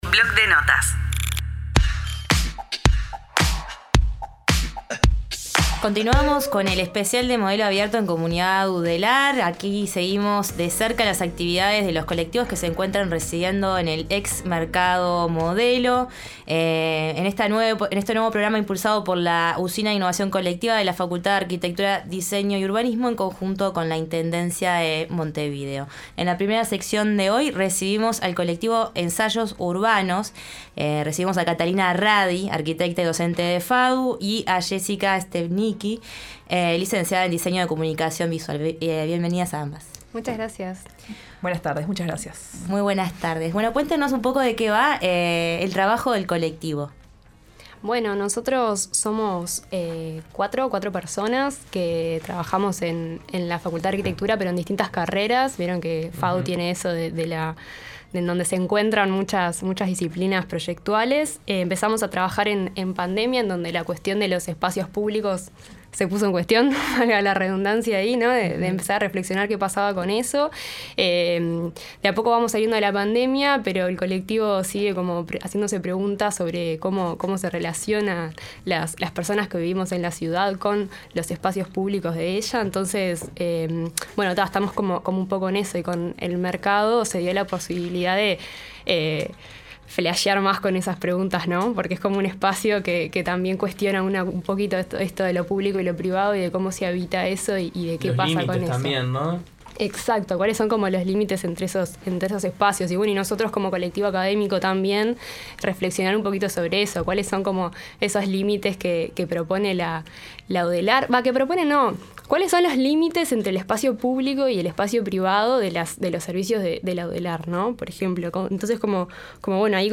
Comunidad Udelar, el periodístico de UNI Radio.